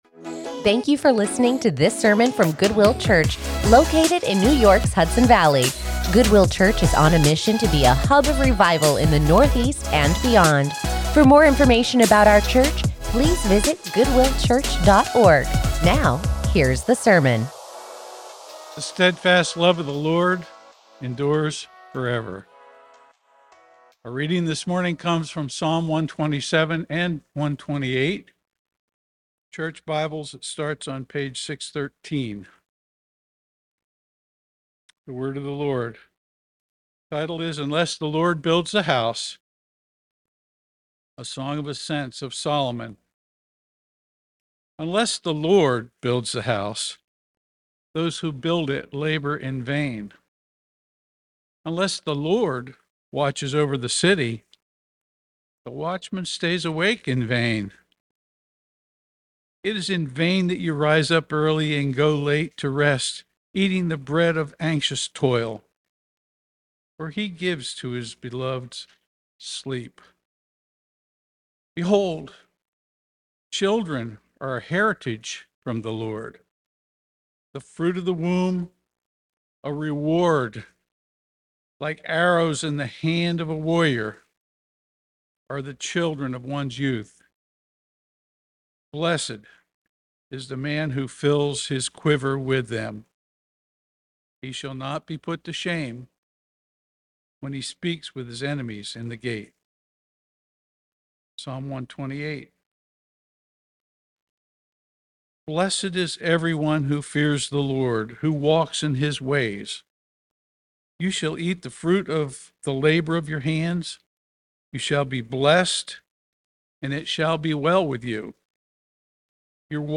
Join us in the study of God's Word as we continue our sermon series